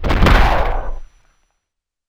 Body_Fall_3.wav